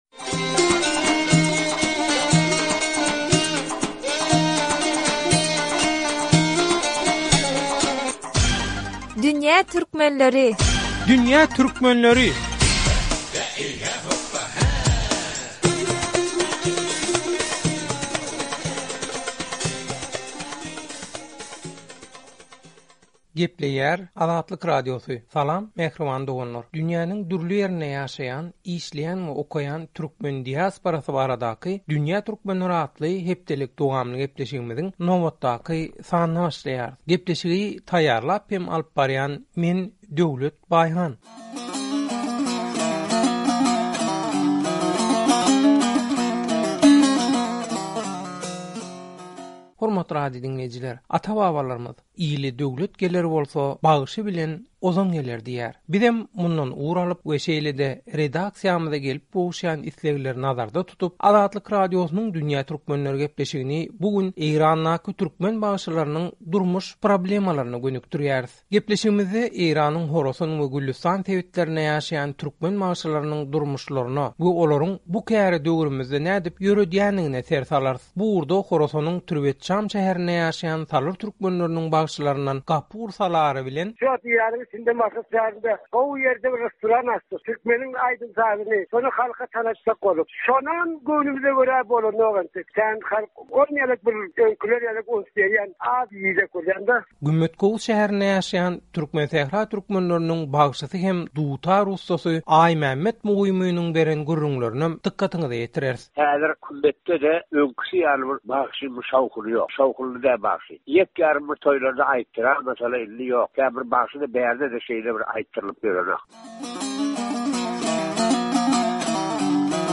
by Azatlyk Radiosy